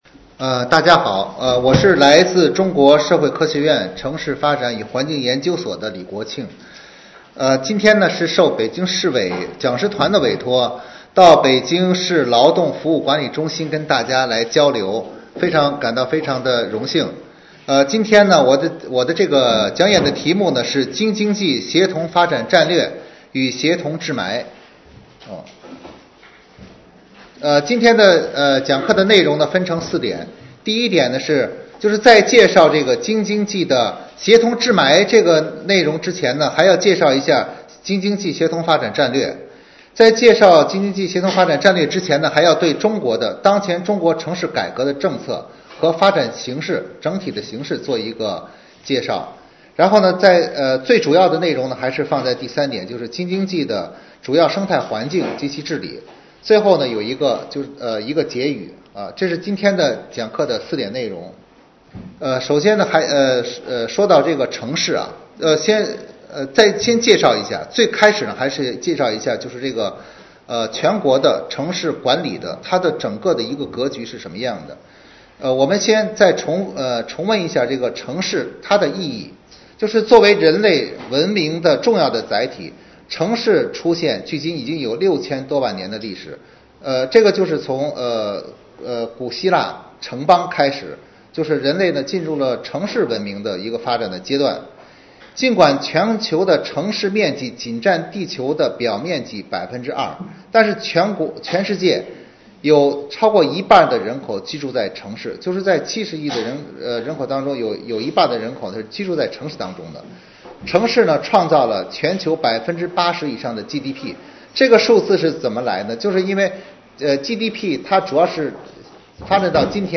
京津冀协同发展战略与协同治霾 − 生态 − 专辑 − 报告 − 宣讲家网